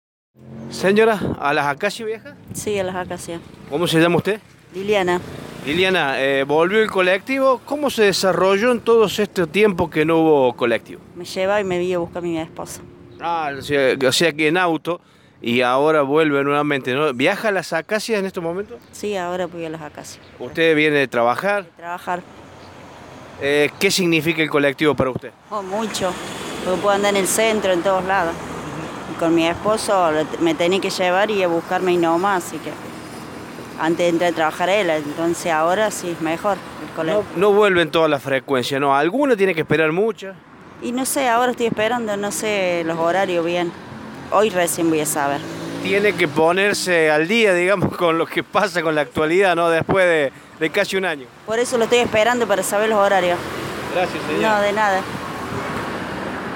Dos pasajeras y un chofer hablaron con Radio Show.